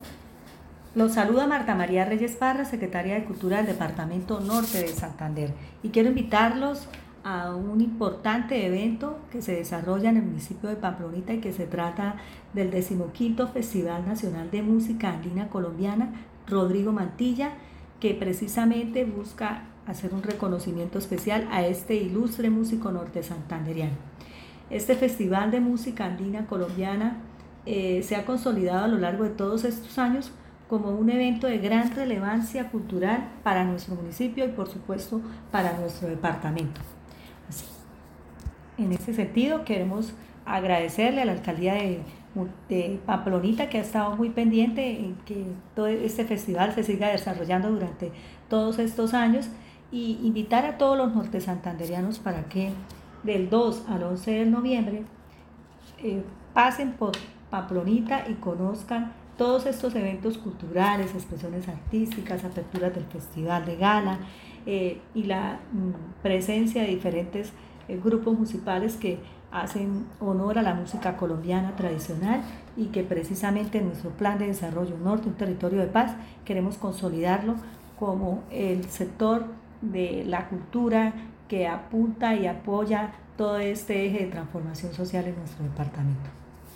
Audio-de-Martha-Reyes-secretaria-de-Cultura.mp3